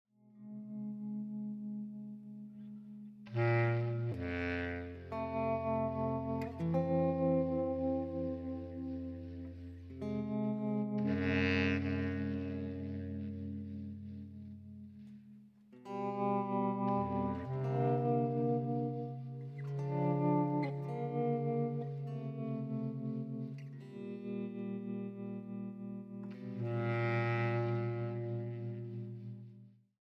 saxophone, bassclarinet
guitar